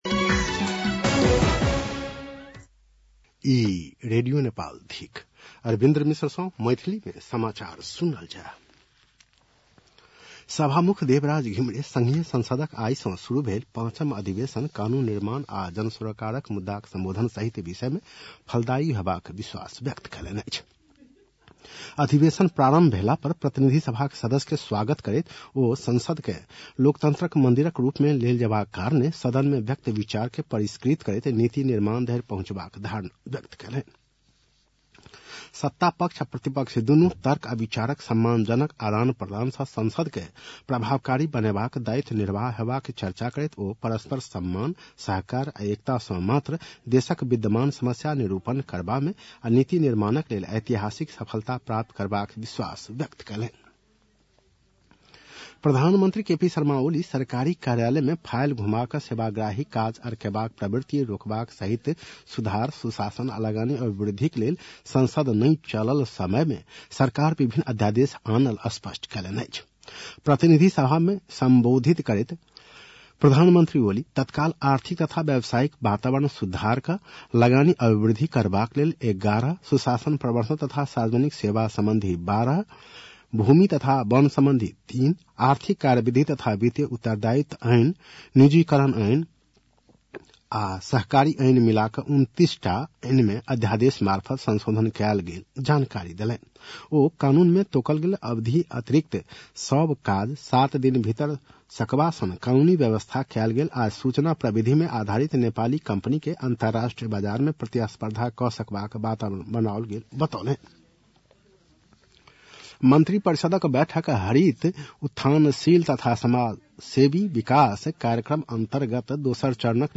मैथिली भाषामा समाचार : १९ माघ , २०८१
MAITHALI-NEWS-10-18.mp3